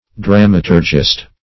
Dramaturgist \Dram"a*tur`gist\, n. One versed in dramaturgy.